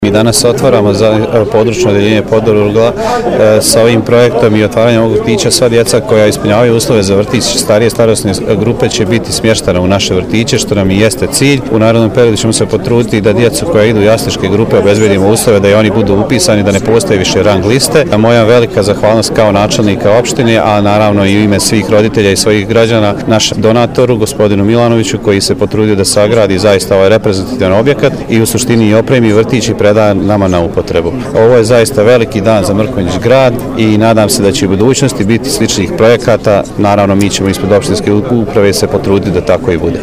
Načelnik opštine Mrkonjić Grad Dragan Vođević zahvalio je u svoje i u ime roditelja donatoru koji se potrudio da izgradi reprezentativan objekat. Napomenuo je da je budući cilj da se obezbijedi prostor za jaslučke grupe, kako bi se ukinule velike rang liste za čekanje mališana na upis.
izjavu